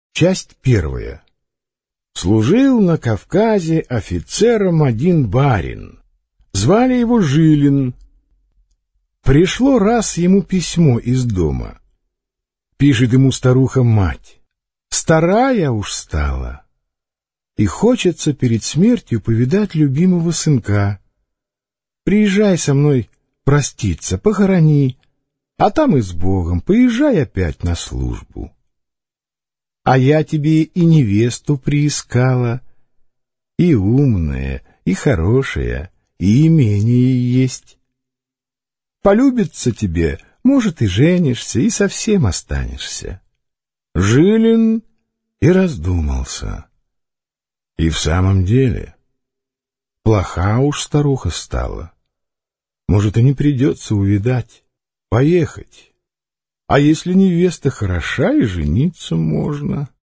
Аудиокнига Исповедь. Повести и рассказы | Библиотека аудиокниг